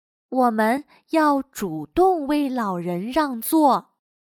我们要主动为老人让座。/Wǒmen yào zhǔdòng wèi lǎorén ràngzuò./Deberíamos tomar la iniciativa de ceder nuestros asientos a los ancianos.